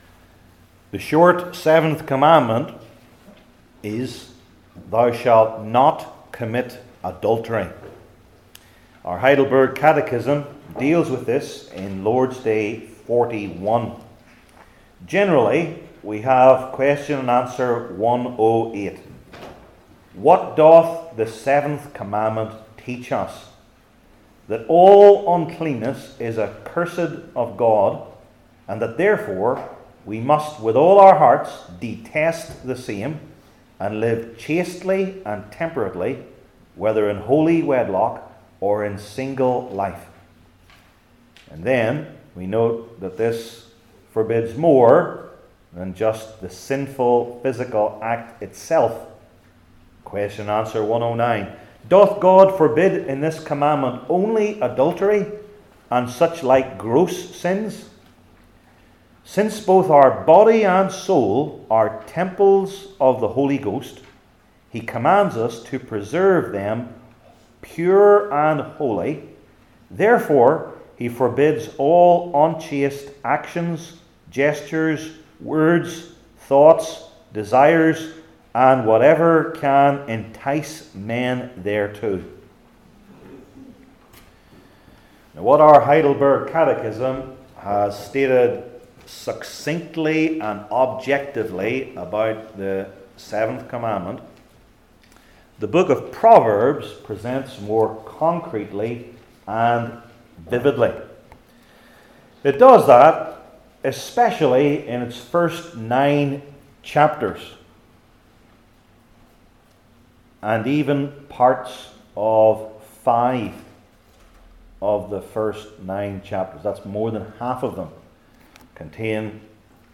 Heidelberg Catechism Sermons I. The Manifold Circumstances II.